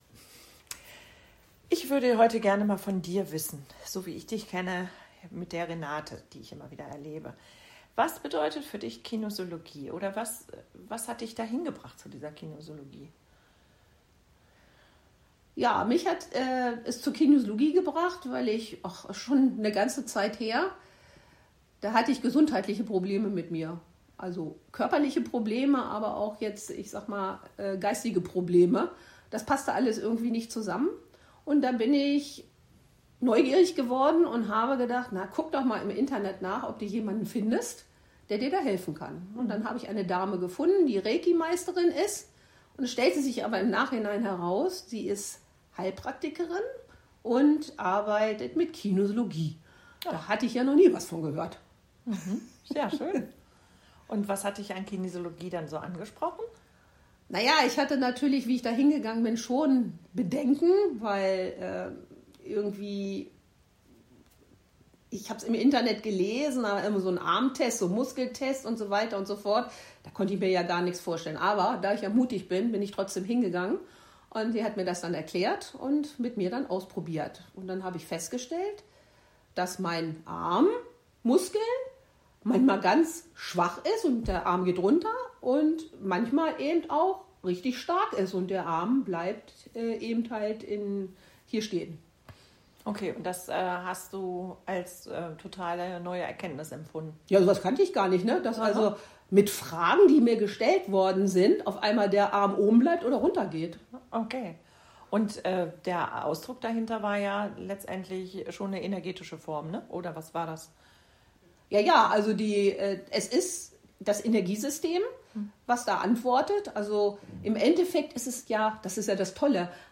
In diesem Podcast erzähle ich dir im Rahmen eines Interviews weitere Erfahrungen mit der Kinesiologie.